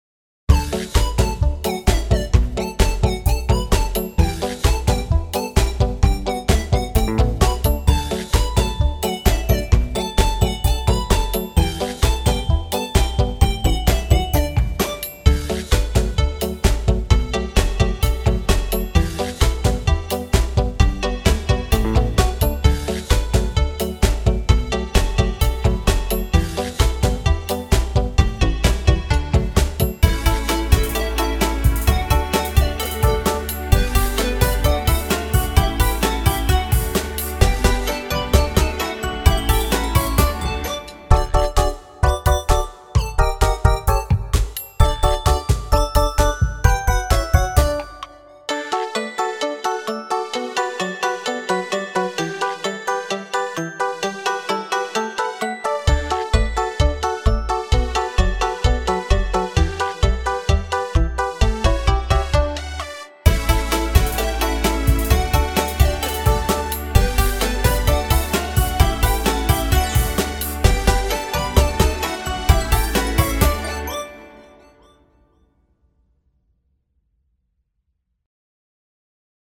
Piesne pre deti a rodinu
vesele-zvieratka-podklad.mp3